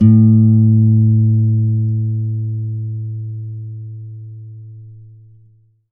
52-str08-abass-a2.aif